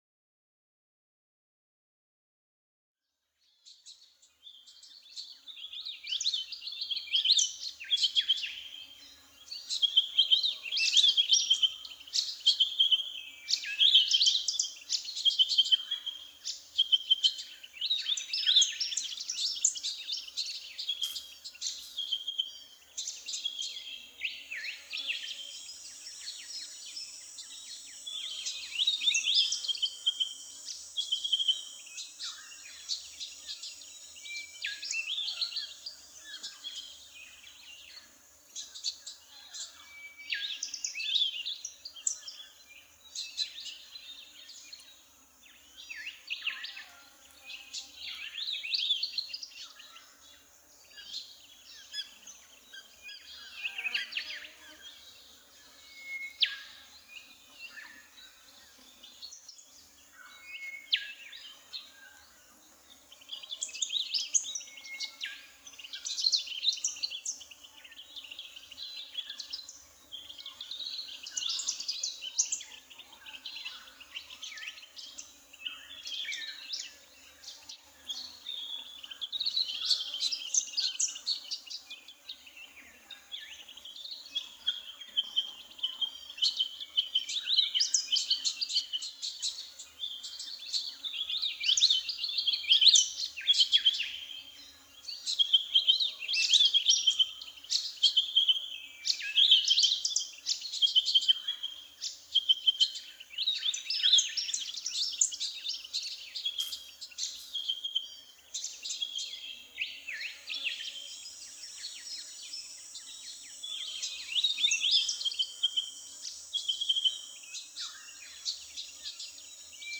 AMB_Scene03_Ambience_LS.ogg